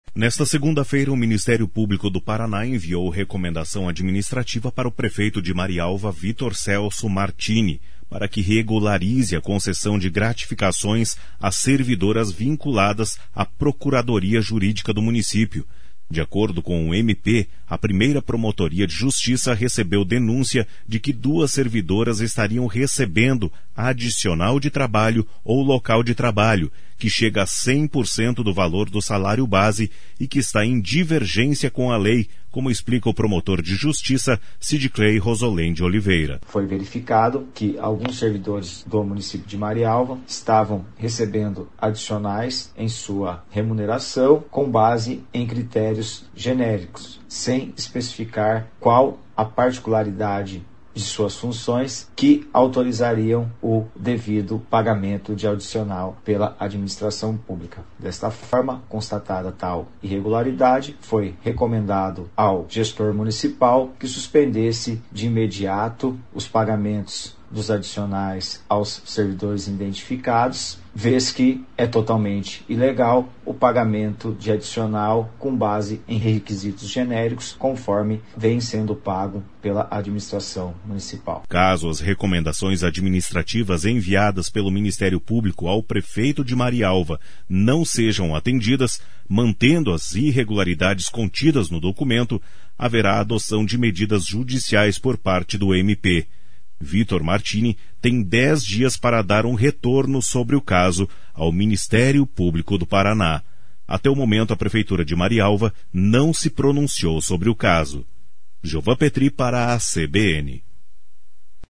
De acordo com o MP, a 1ª Promotoria de Justiça, recebeu denúncia de que duas servidoras estariam recebendo "adicional de trabalho ou local de trabalho" que chega a 100% do valor do salário base e que está em divergência com a lei como explica o promotor de Justiça Sidclei Rosolen de Oliveira.